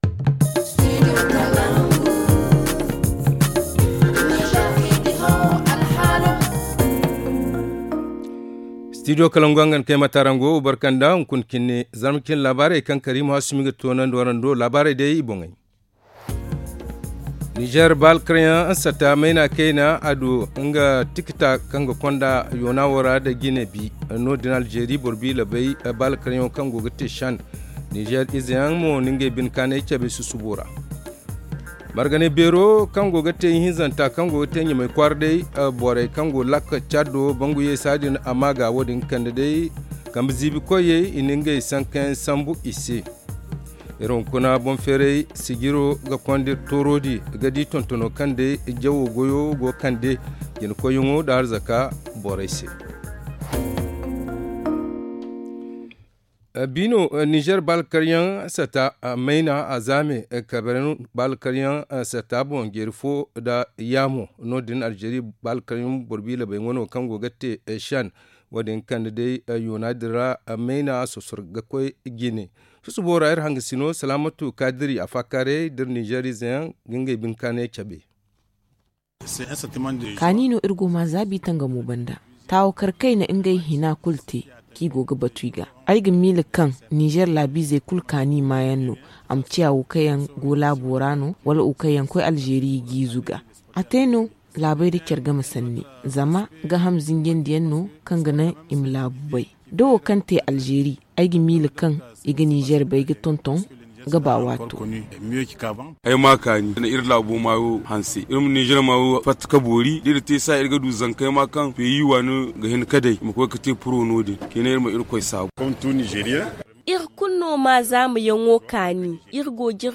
Le journal du 25 janvier 2023 - Studio Kalangou - Au rythme du Niger